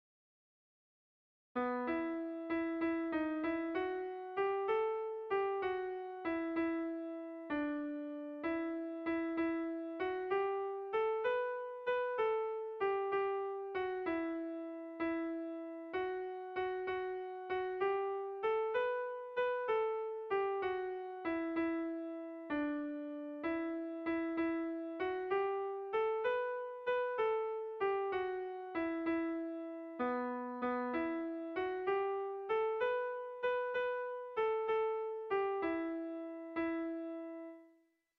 Erlijiozkoa
Hamarreko txikia (hg) / Bost puntuko txikia (ip)
AB1B2B1B3